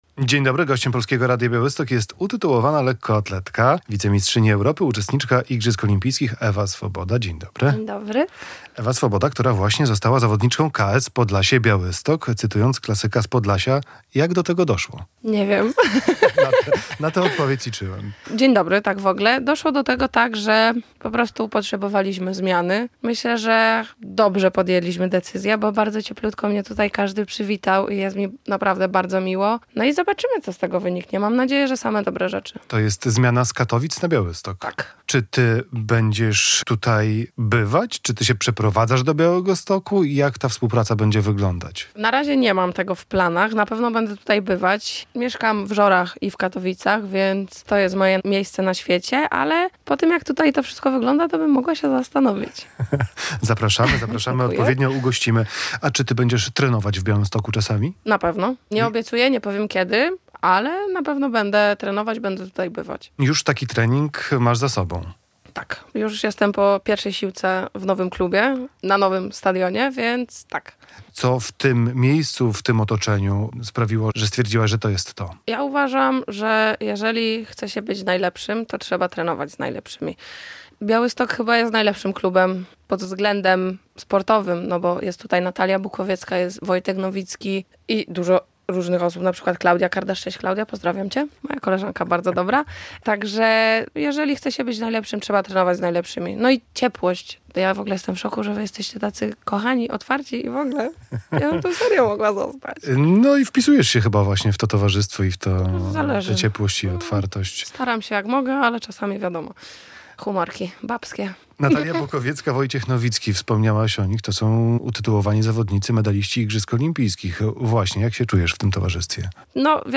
Radio Białystok | Gość | Ewa Swoboda - lekkoatletka